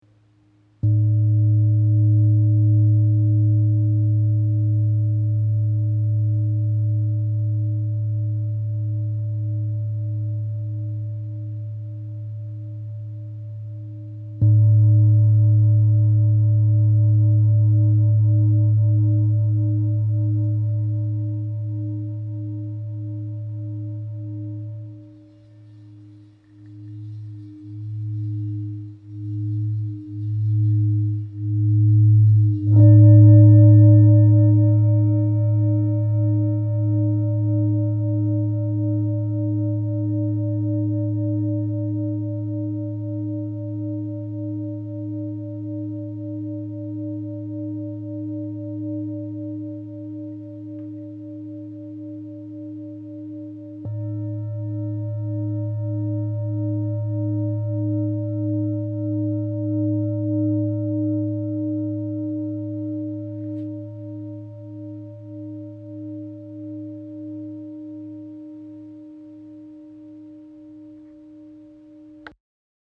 Bol tibétain Ulta Bathi • La 53Hz
Le bol Ulta Bathi est réputé pour sa résonance grave et vibrante. Ses vibrations basses et prolongées font de lui un allié idéal pour ceux qui recherchent un instrument puissant et efficace pour le travail énergétique et l’harmonisation intérieure.
Note : La 53 Hz
Diamètre : 26,6 cm